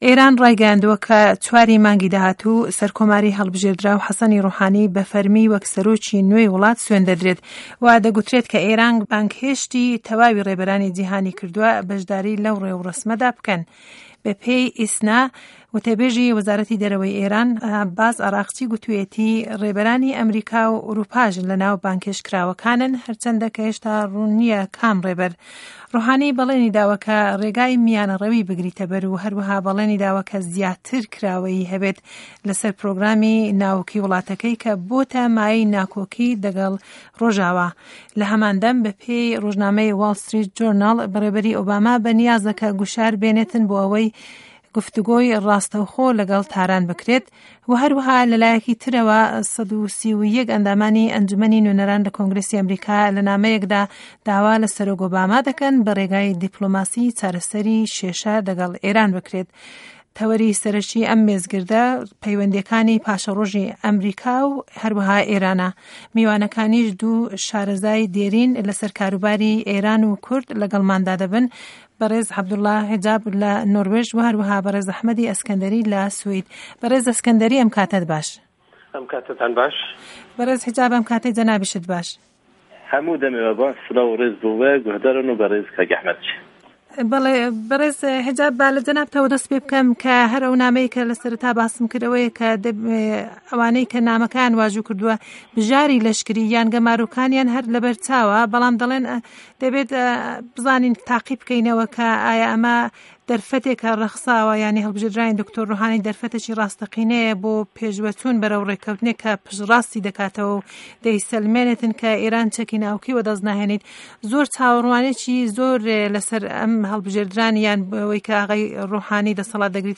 مێزگرد: په‌یوه‌ندییه‌کانی ئه‌مه‌ریکاو ئێران